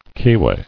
[key·way]